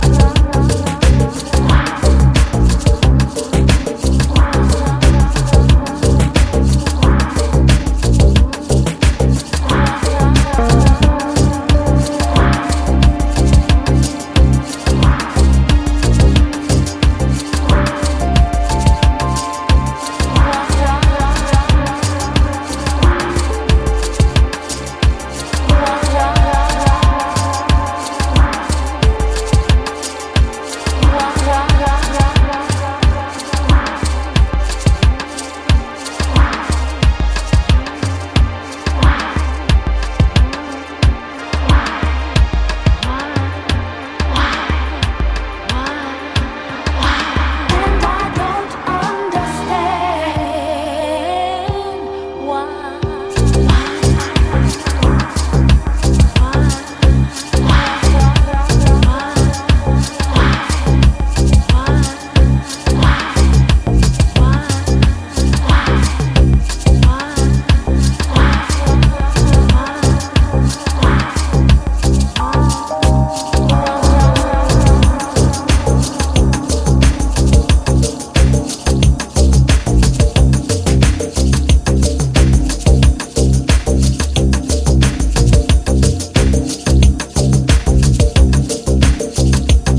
ソウルフルで黒いビートダウン・ハウスを中心とした濃厚な2枚組！